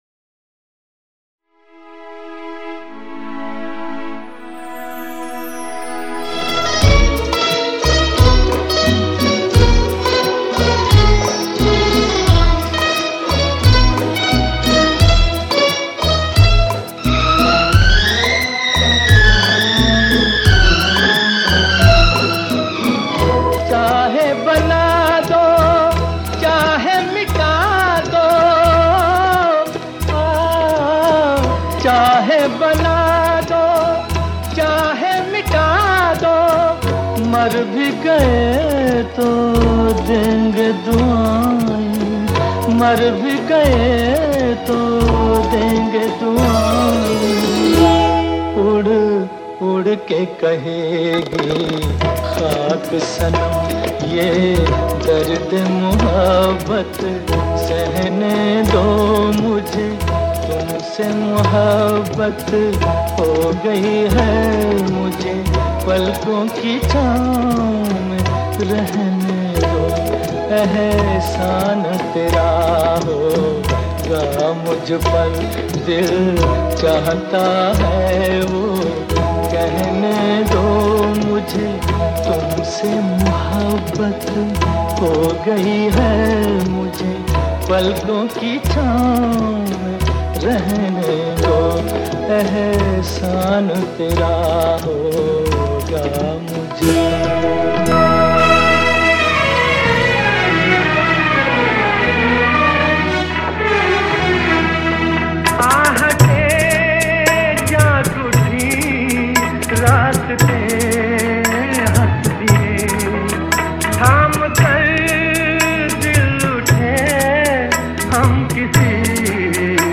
Karaoke Version Video Lyrics Track